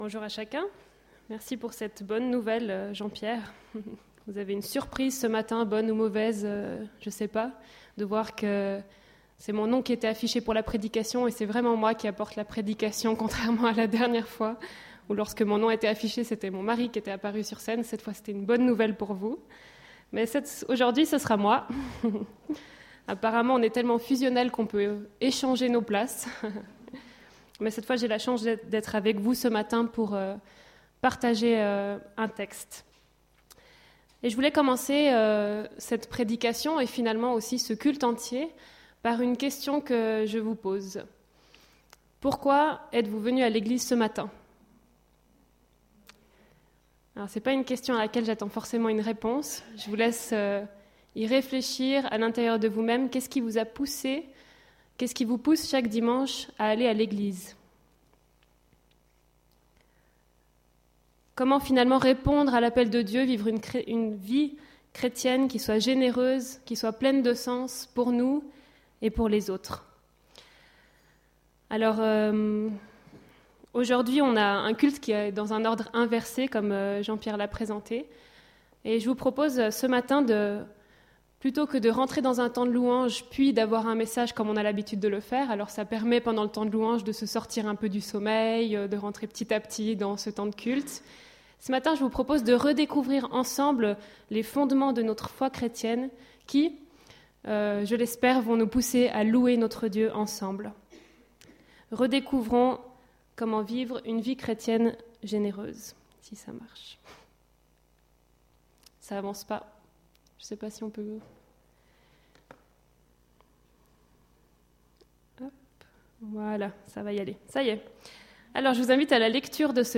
Culte du 22 novembre 2015